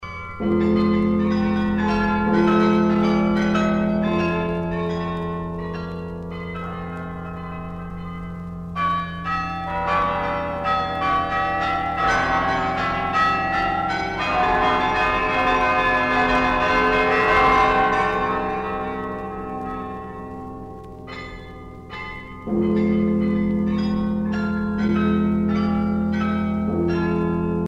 carillon de Malines